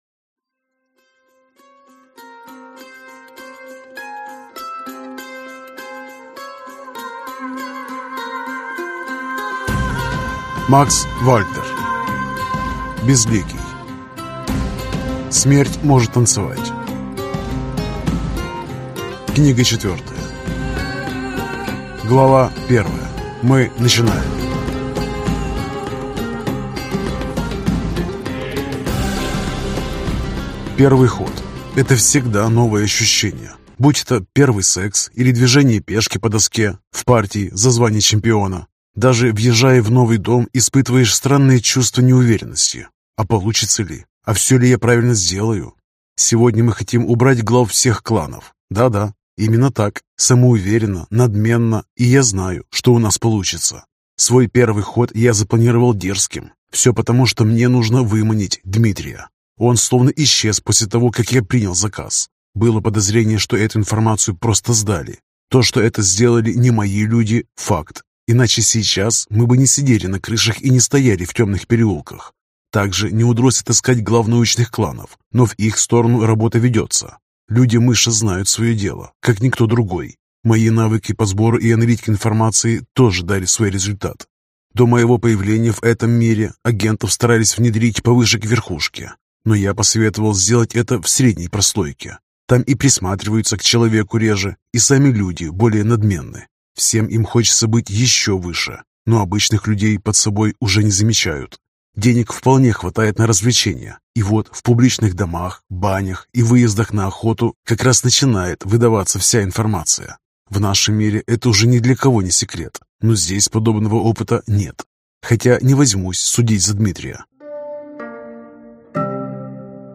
Аудиокнига Смерть может танцевать 4 | Библиотека аудиокниг